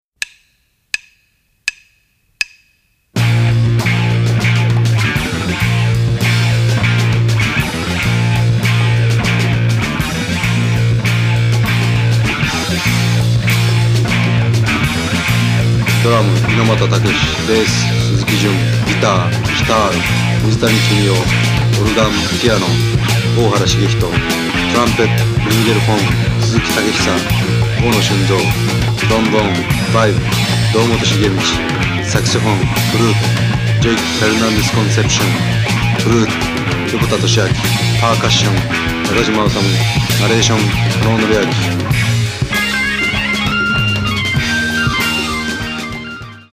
drums
trumpet/flugelhorn
elec. piano/Hammond Organ/piano
sop. & alt. & ten. sax/flute
elec.guitar/fork guitar/sitar